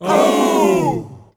OUUUUH.wav